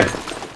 pl_step4.wav